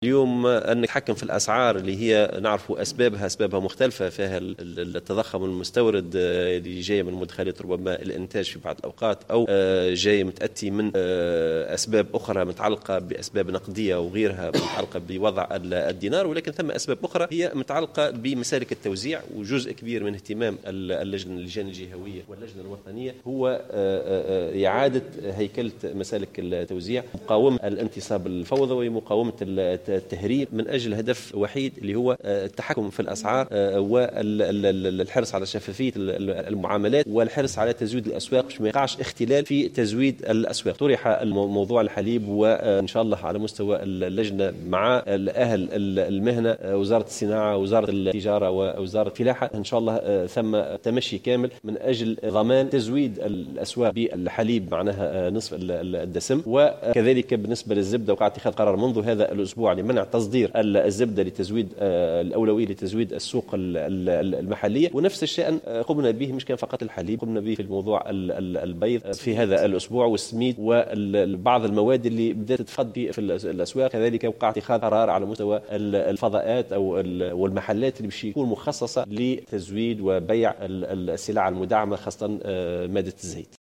أكد الوزير المستشار لدى رئيس الحكومة المكلف بمتابعة المشاريع والبرامج العمومية، رضا السعيدي في تصريح للجوهرة "اف ام" اليوم الخميس أن الإنفلات الملحوظ في الأسعار يعود إلى عدة أسباب أهمها تراجع قيمة الدينار اضافة إلى كثرة مسالك التوزيع الموازية وغير القانونية.